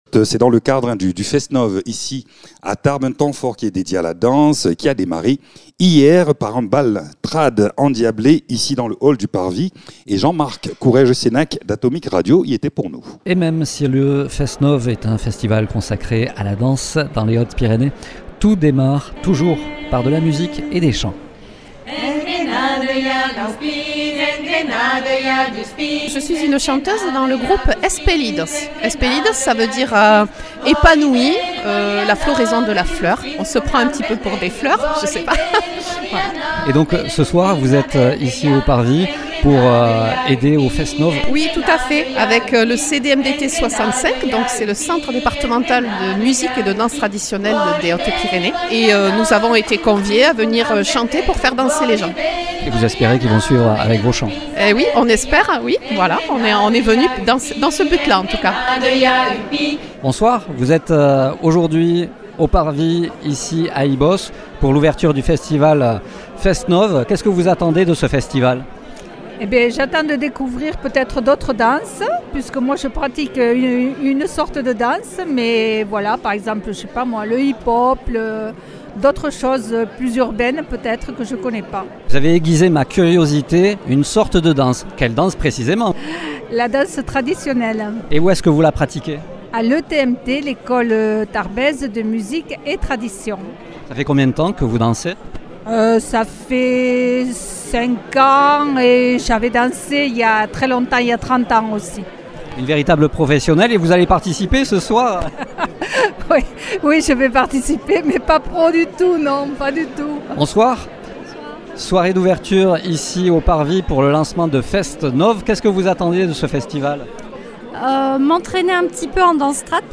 Reportage bal traditionnel